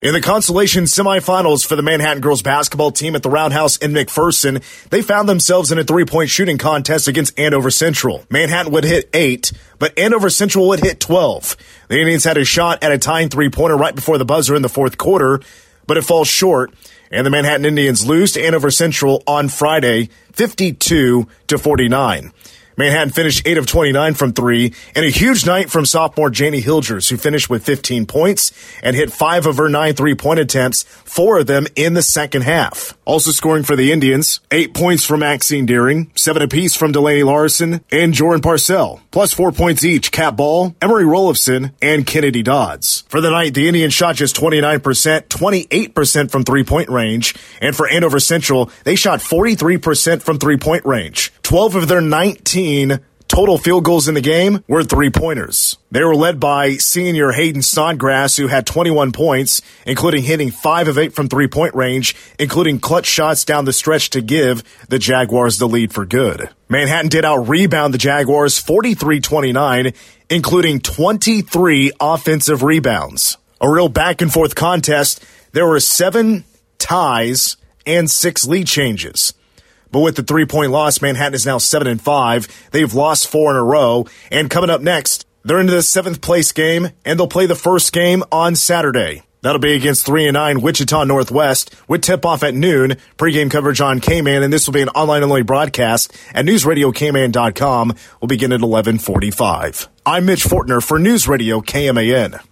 Game recap